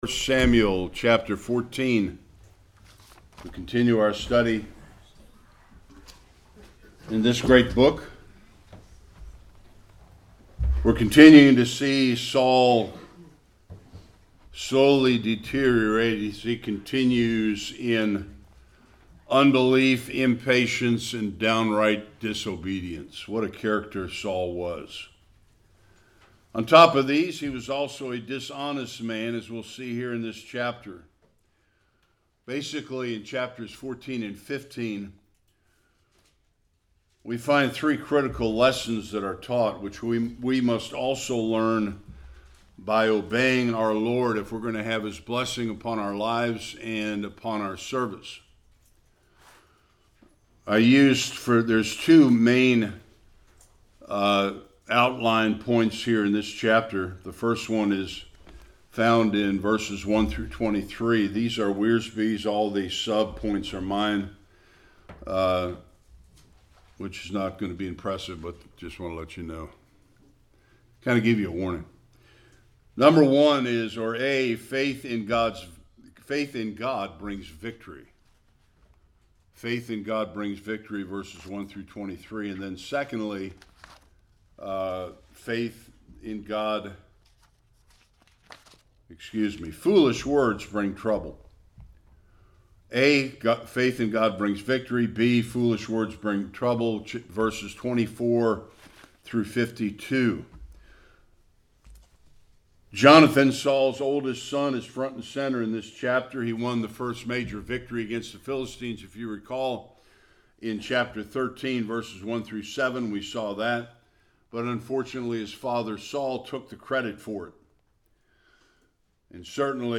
1-23 Service Type: Sunday School Jonathan’s victory over the Philistines in spite of King Saul’s foolish vow.